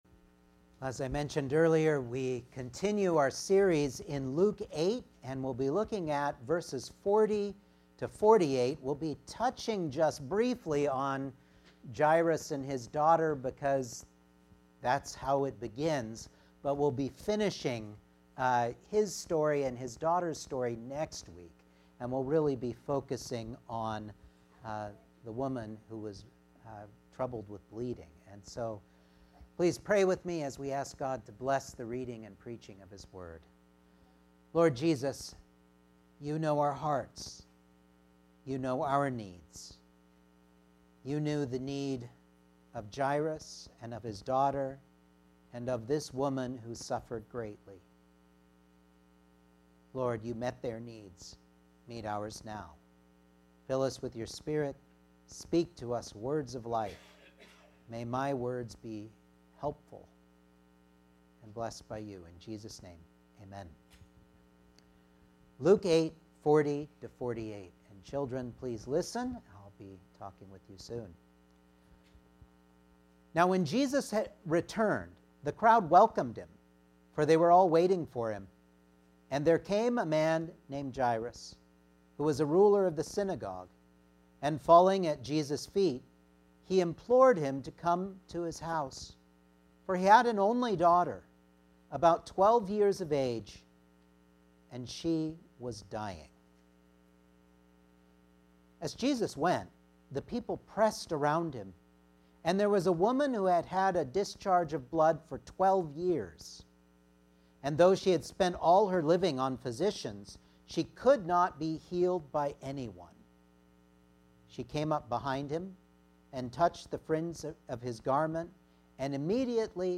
Luke 8:40-48 Service Type: Sunday Morning Outline